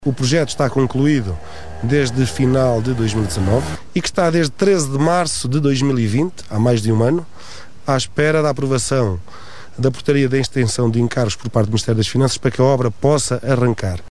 O autarca Paulo Almeida diz que circular na EN 225 é um desafio diário e que coloca em causa a segurança dos condutores.